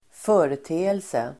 Ladda ner uttalet
Uttal: [²f'ö:rete:else]